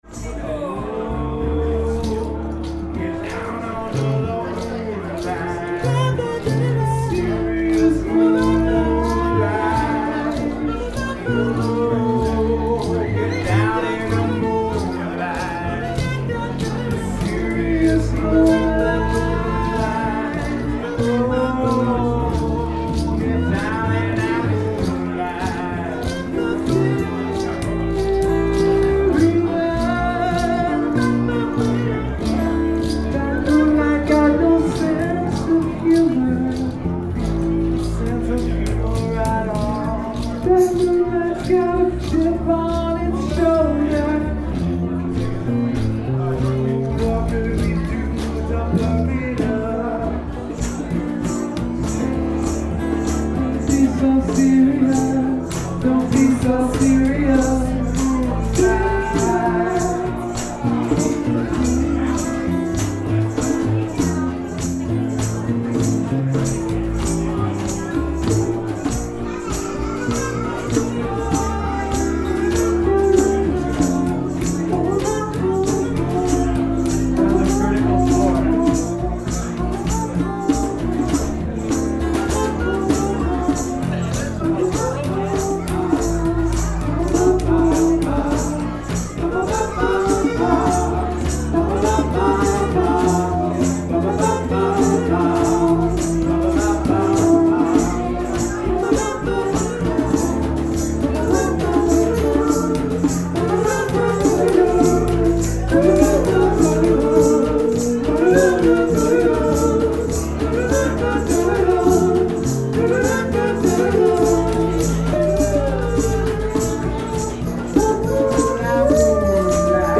Set 1 (49 megs) Set 2 (52 megs) Here are the recordings from our November 10th show at the Enlighten Cafe.
guitar and voice
djembe
… first time out on banjo!
uke and percussion
keys and voice
bassbox and voice
Even with the extra-jumbo lineup, the mix felt balanced and well-supported throughout, and the playing responded in kind.
I also noticed two subtle changes that seemed to open things up: One was the increase in complicated and jazzy changes (lots of major 7ths and 9's), which just felt so rich with the easy momentum of the big group. And the other difference was signaling more of the changes to cycle at about half the rate that we usually cycle them (each chord held for two or more measures, instead of a quick 4-count).